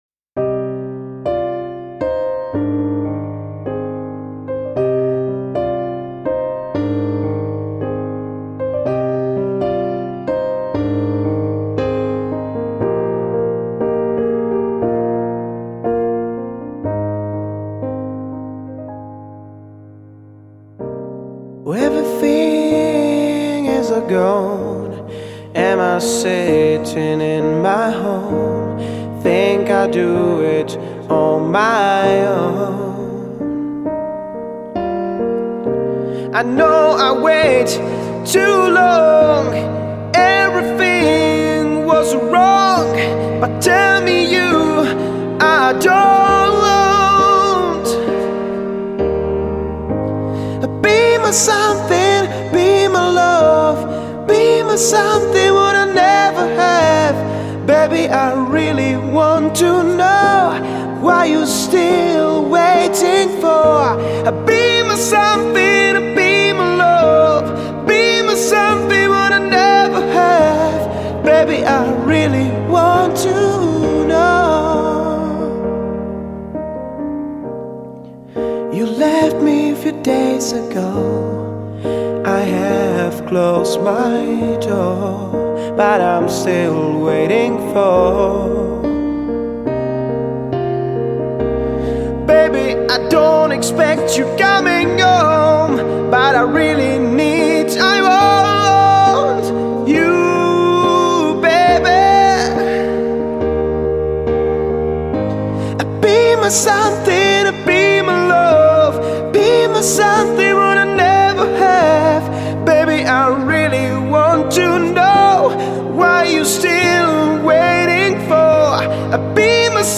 Žánr: Pop
CD bylo nahráno ve známém ostravském studiu Citron.